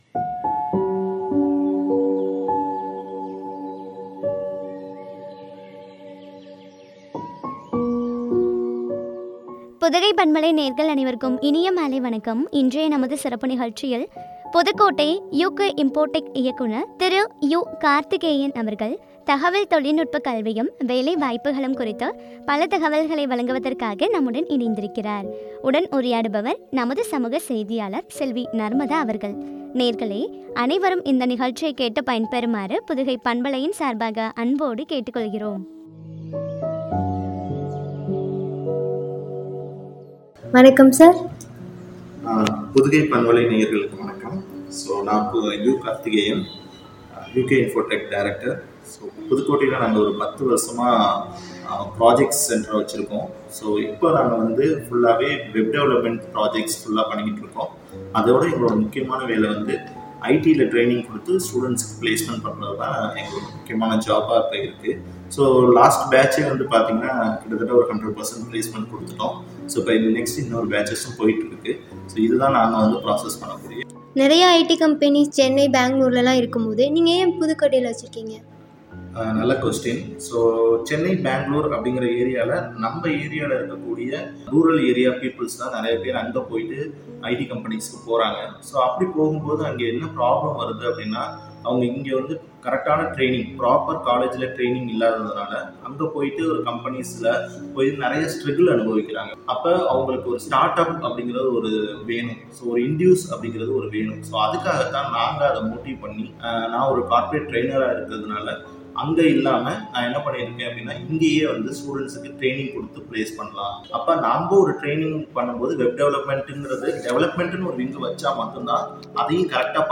தகவல் தொழில்நுட்ப கல்வியும், வேலைவாய்ப்புகளும் பற்றிய உரையாடல்.